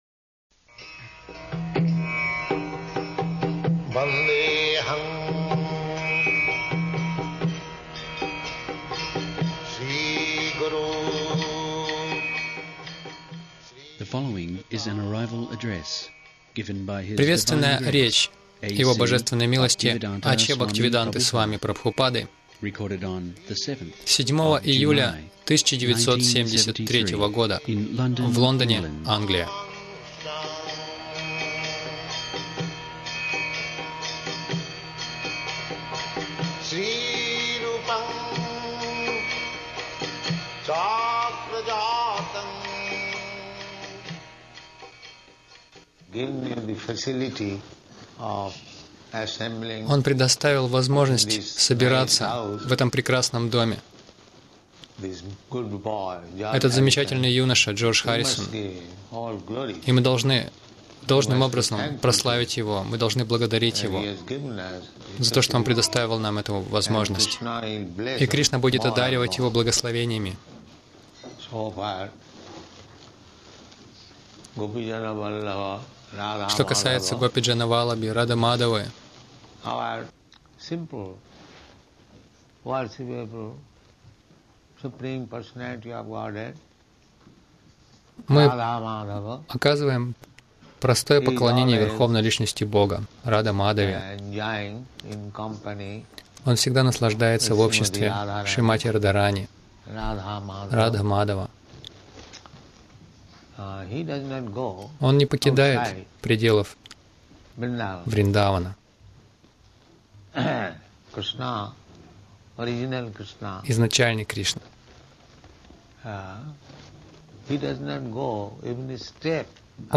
Лекция после прибытия в Лондон — Как отыскать Бога